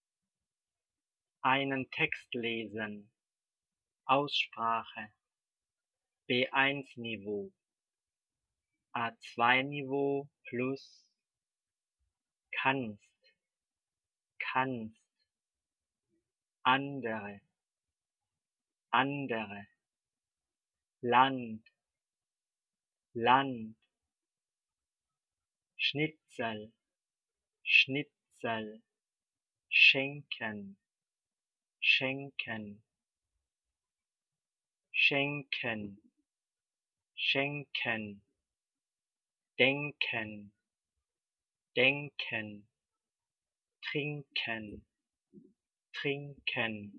Teil 1: an / sch / nk